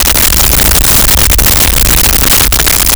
Sci Fi Drone Loop
Sci Fi Drone Loop.wav